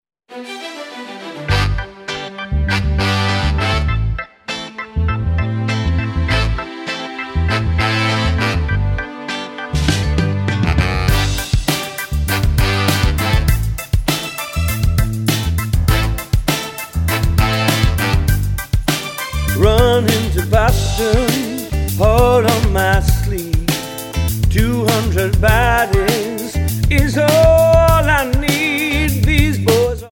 Tonart:G Multifile (kein Sofortdownload.
Die besten Playbacks Instrumentals und Karaoke Versionen .